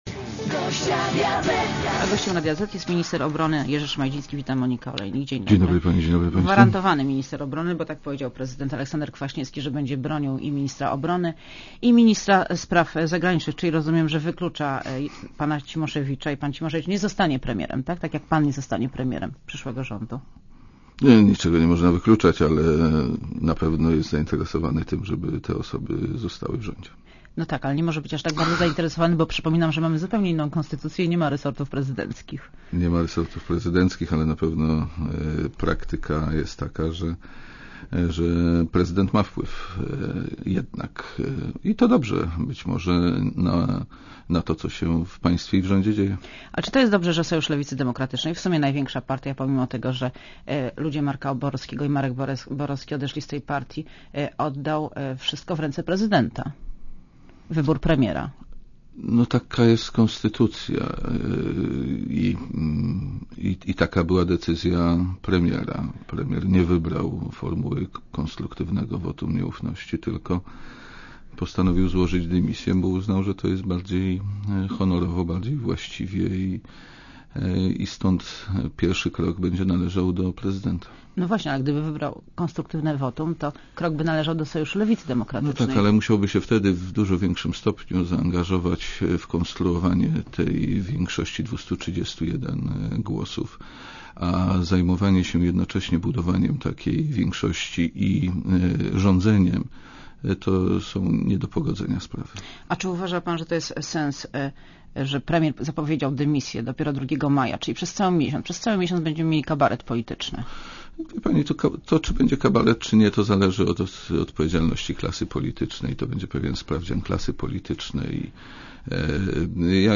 Jerzy Szmajdziński w Radiu Zet (RadioZet)